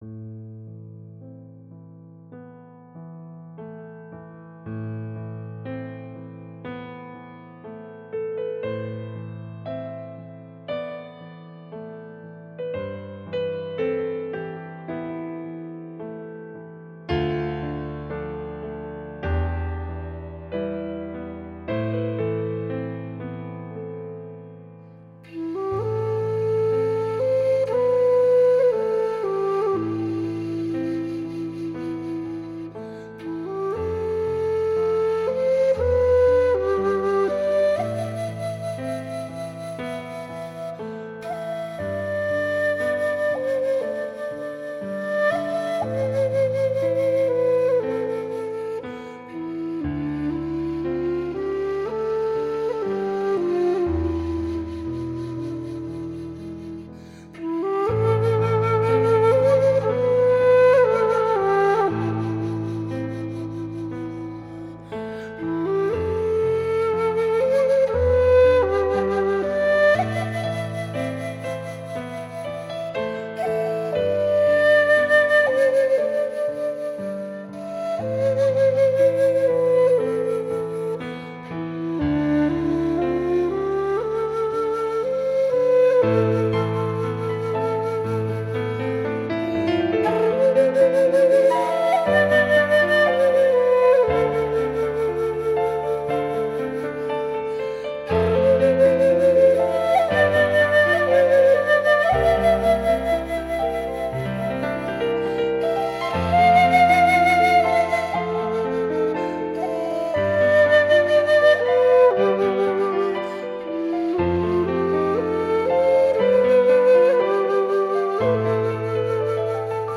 风格独特 气息敦厚稳健 音色圆润甜美，清脆响亮 自然流畅
华丽时光彩夺目 细致时玲珑剔透 情绪交替犹如行云流水